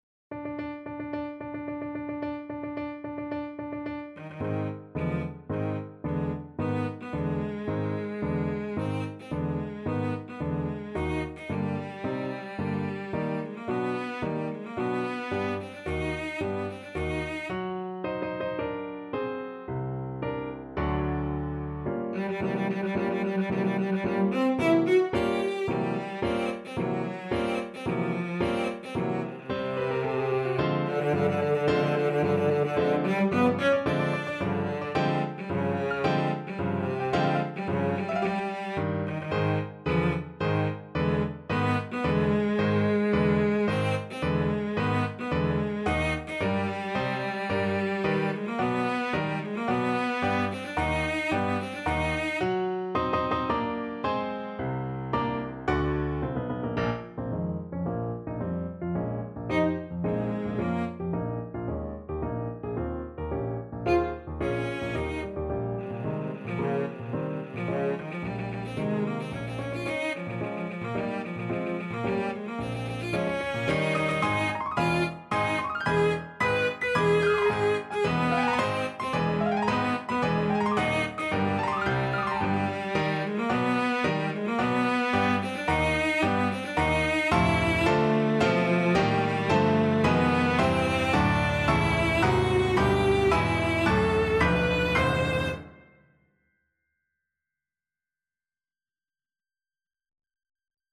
4/4 (View more 4/4 Music)
~ = 110 Tempo di Marcia
Classical (View more Classical Cello Music)